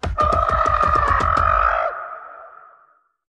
Sukoroar2.ogg